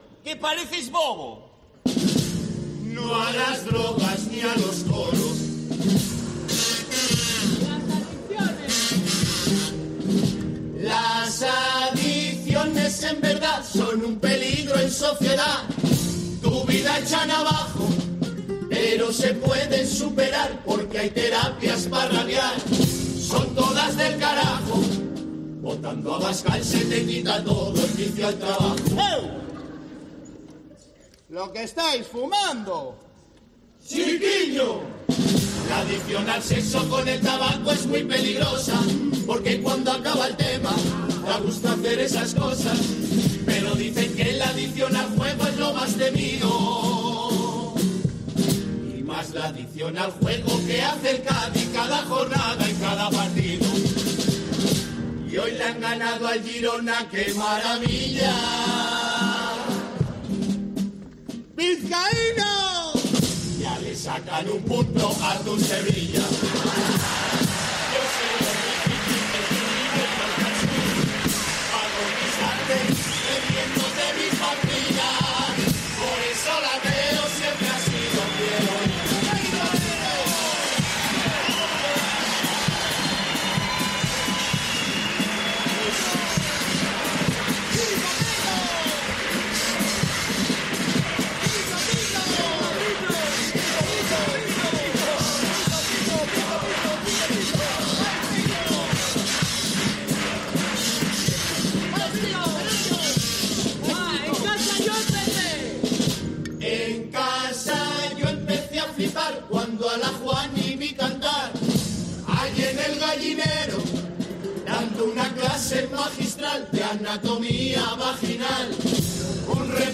Los cuplés de 'Fariña de mis ojos' en cuartos de final
Carnaval